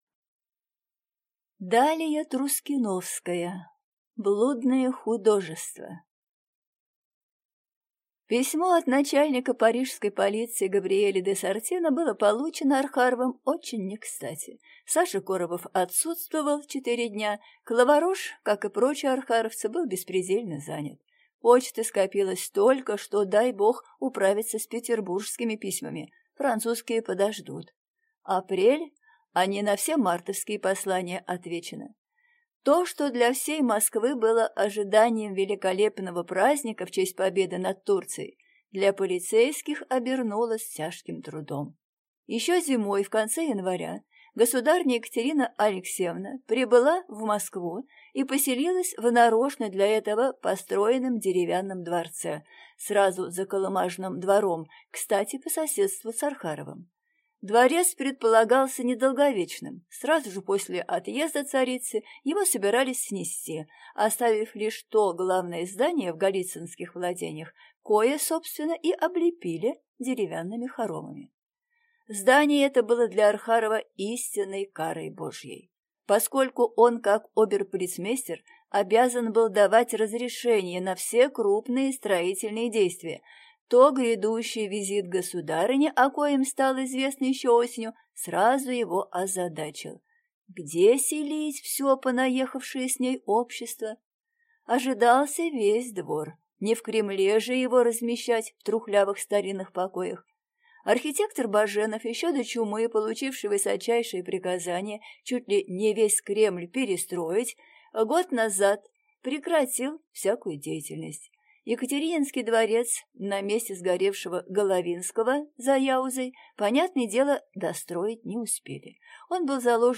Аудиокнига Блудное художество | Библиотека аудиокниг